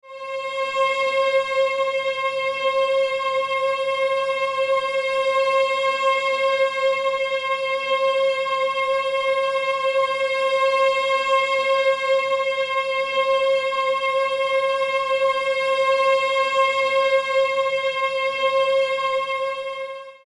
Music tones for String Animation Below
30_High_C.mp3